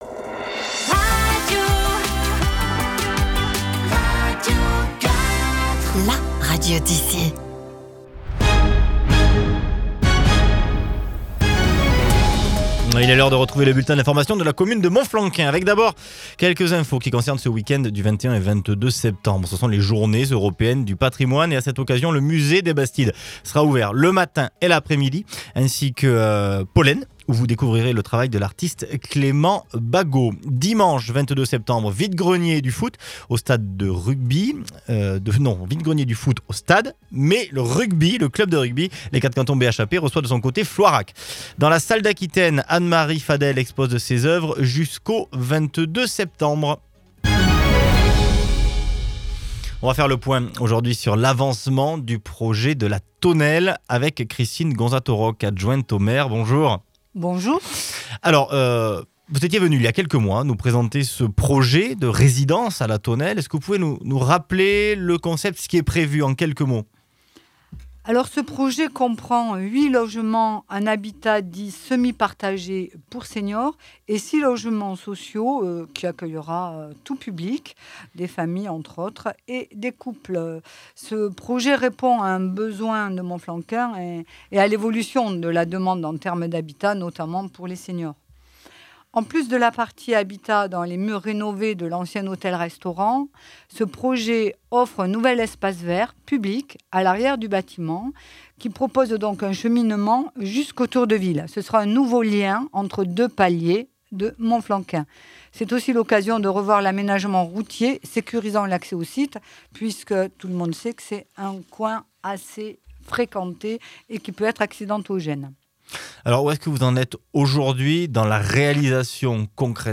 VOS ÉLU(E)S  à la RADIO